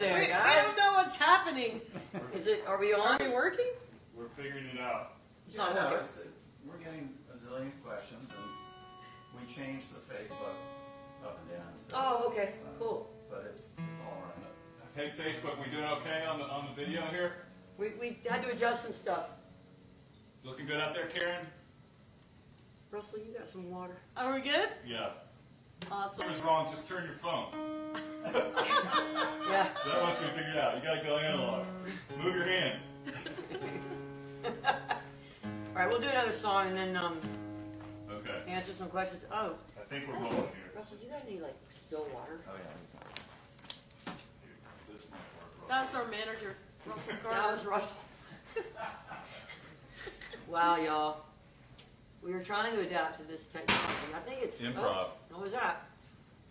(captured from a facebook live stream)
05. talking with the crowd (cut) (0:56)